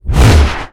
MAGIC_SPELL_Power_mono.wav